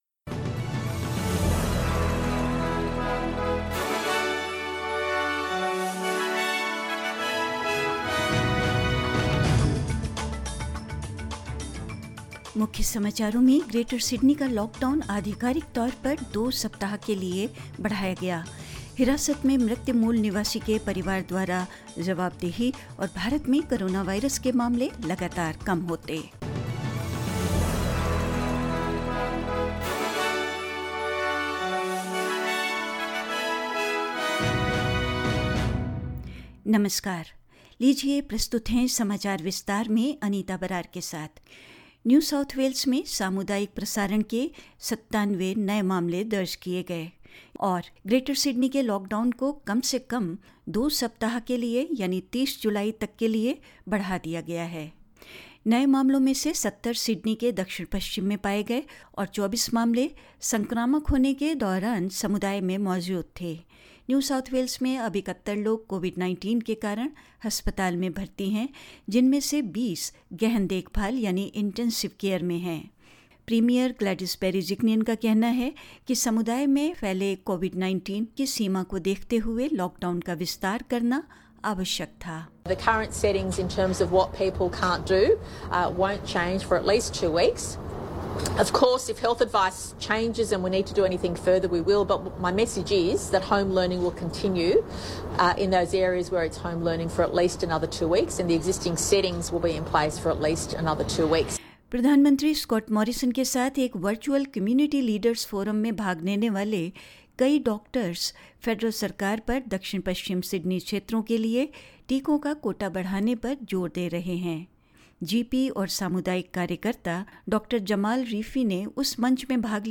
In this latest SBS Hindi News bulletin of Australia and India: Greater Sydney's lockdown is extended for at least two more weeks, and New South Wales recorded 97 new cases of community transmission; The family of a First Nations man who died in custody demand answers; In India, Kerala reports more new cases of Zika virus and more news